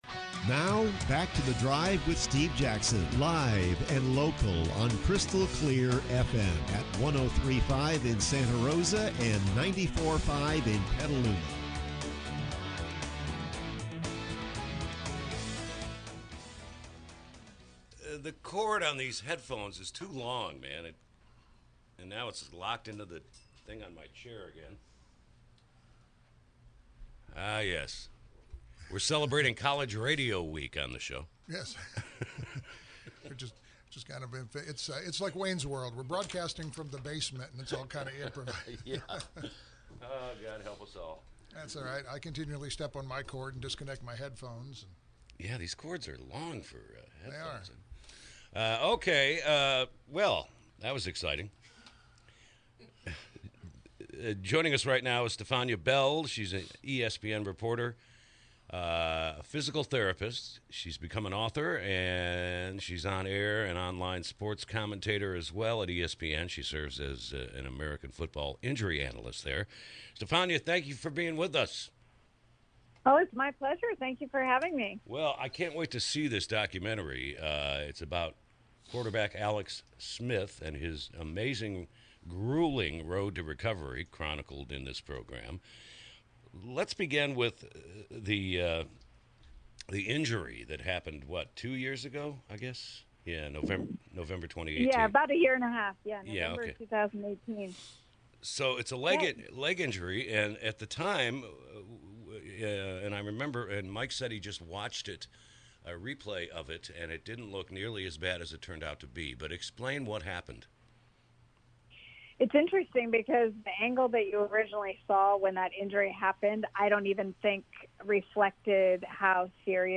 Listen to complete interview with ESPN’s Stephania Bell